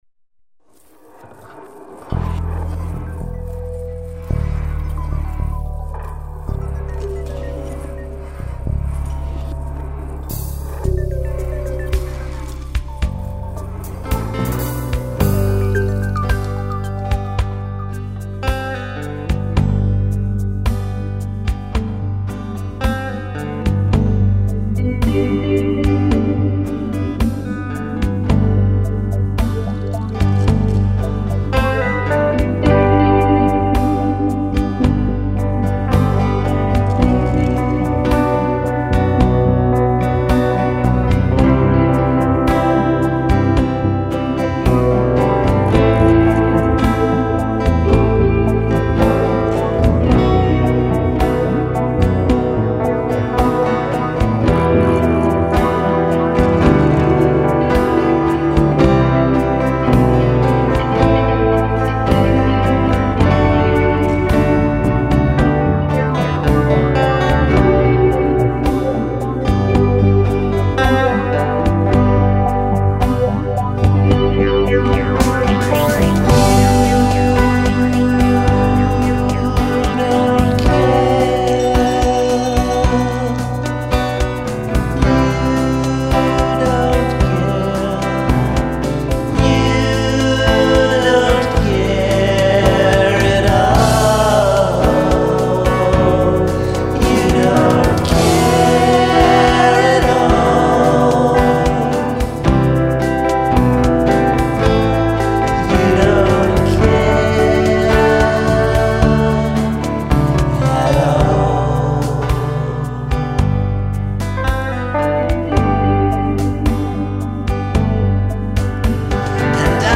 I've recorded a new original song tentatively titled Marconi the Barber . This is fresh off the presses as of Sunday, and I think I'm happy with the final mix.